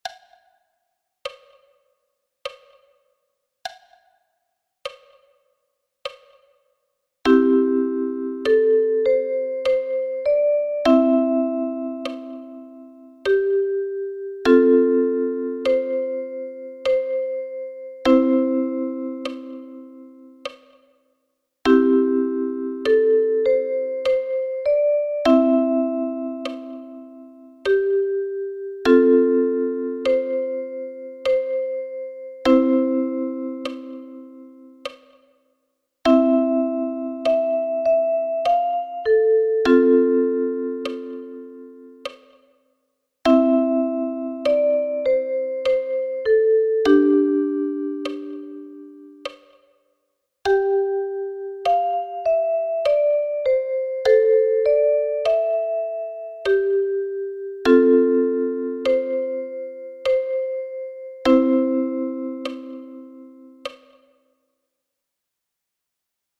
Play Ukulele – 41 arrangements of traditionals from Ireland & Great Britain